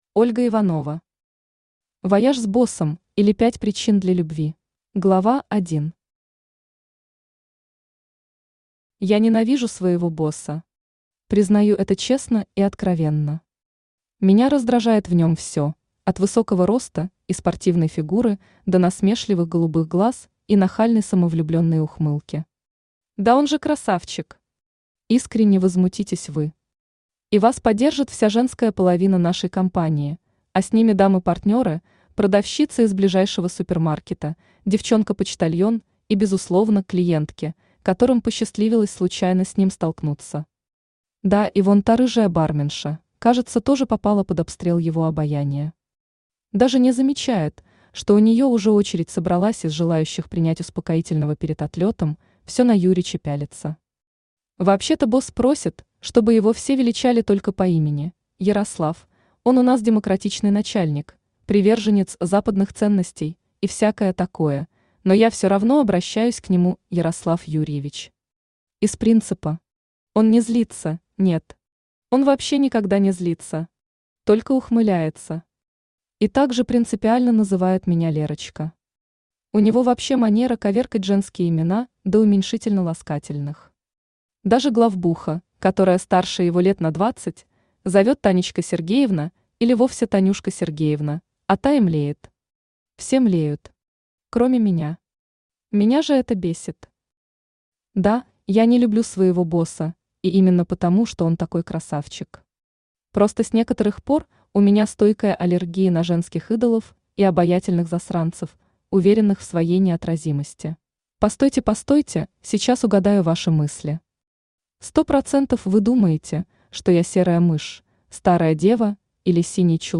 Аудиокнига Вояж с боссом, или 5 причин для любви | Библиотека аудиокниг
Aудиокнига Вояж с боссом, или 5 причин для любви Автор Ольга Дмитриевна Иванова Читает аудиокнигу Авточтец ЛитРес.